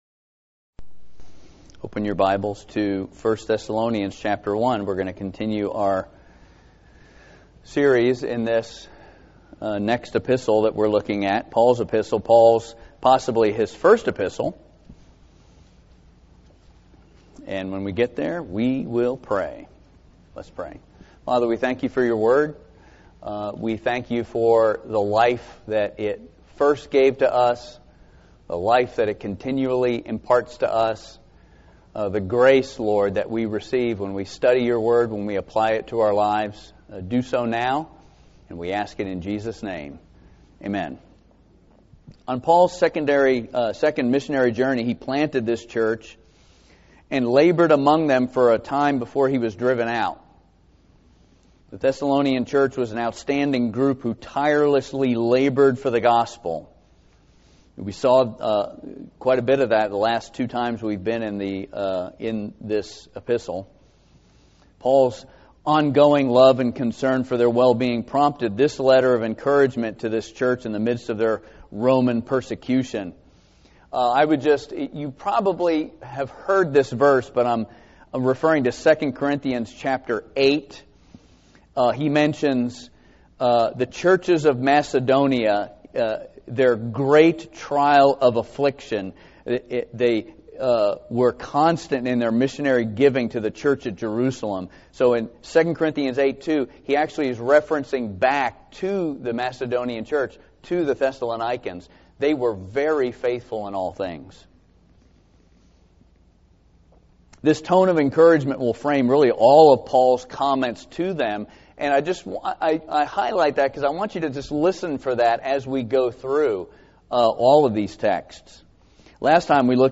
Sermon Archives 2022